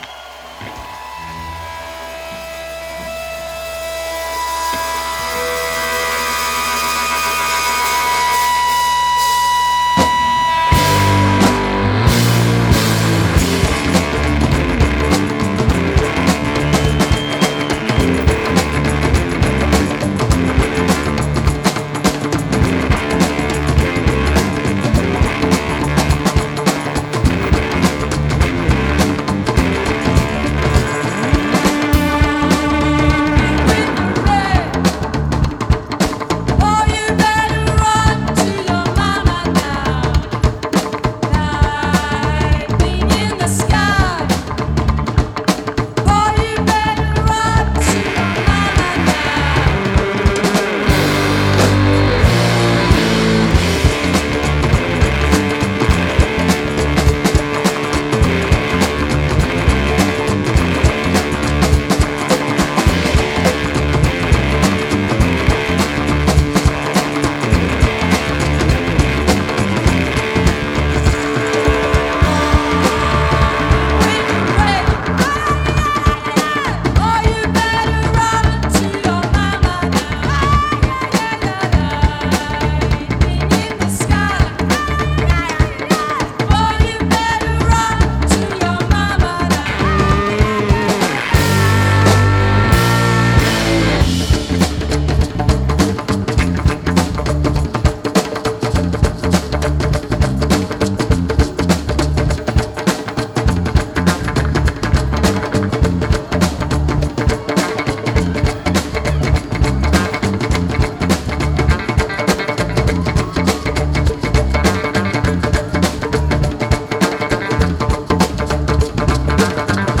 La transe de la drache du tonnerre.